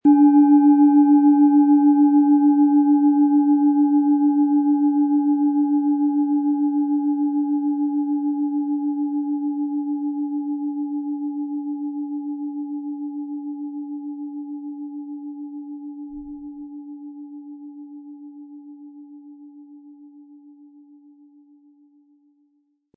Planetenschale® Angeregt fühlen & Kraftvoll und tatkräftig sein mit Mars, Ø 14 cm, 400-500 Gramm inkl. Klöppel
Planetenton
SchalenformOrissa
MaterialBronze